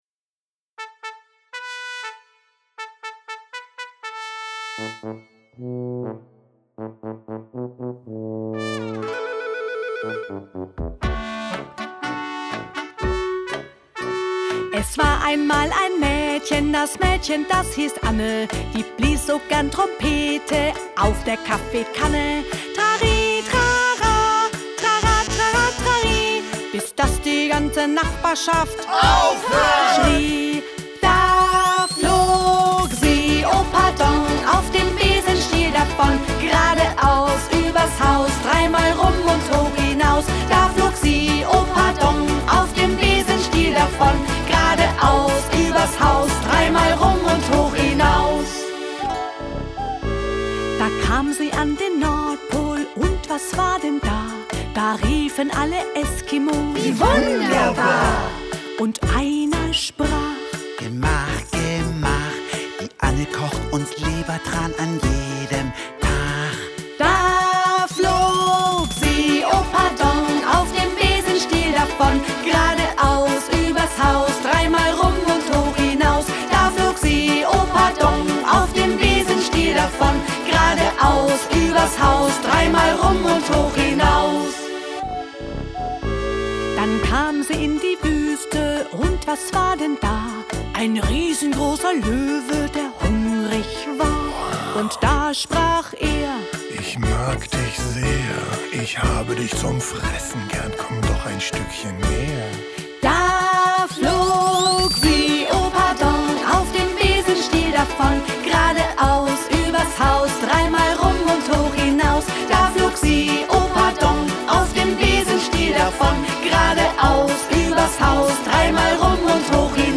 Elf Minutengeschichten laden die Kleinsten zum Zuhören und zum Mitraten in den Bonustracks ein. Fröhliche Lieder von bekannten Kinderliedermachern ergänzen die Geschichten.